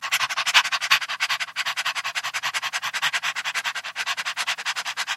Звуки запыхавшегося человека
Есть комедийный вариант